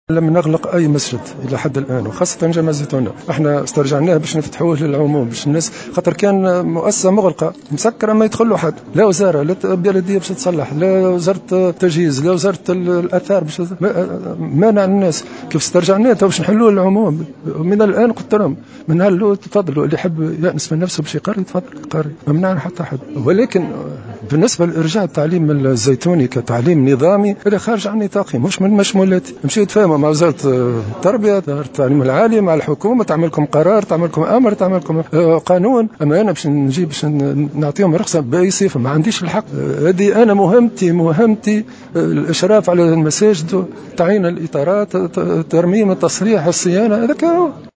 وقال بطيخ في تصريح للجوهرة أف أم على هامش زيارة أداها اليوم السبت إلى ولاية بنزرت، إن مهمته الأساسية كوزير للشؤون الدينية تتمثل في الإشراف على المساجد وتعيين الإطارات والترميم والصيانة بمختلف بيوت الله.